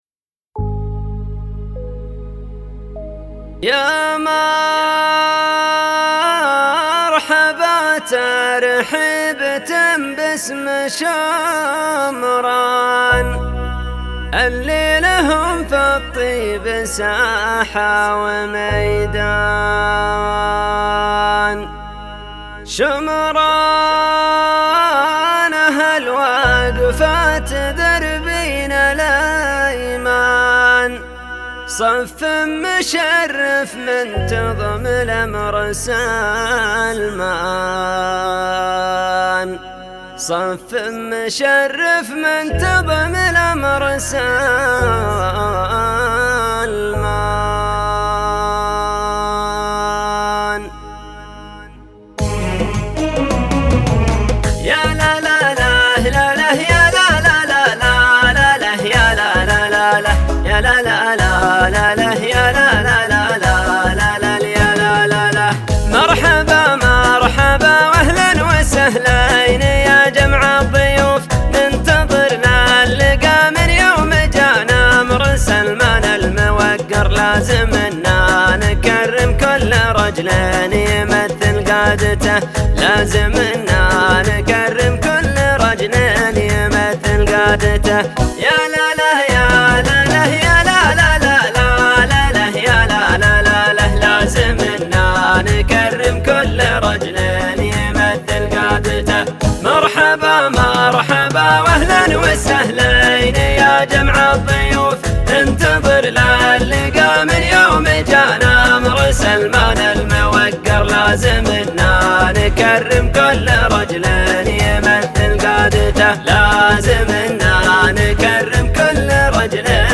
في ليلة احتفاء استثنائية مُزجت بالوفاء والكرم والبخور والعود
في ليلة احتفاء استثنائية مُزجت بالوفاء والكرم والبخور والعود والصوت العذب أحتفى العقيد الشيخ :
فقد عانقت السحاب نظما وصوتا: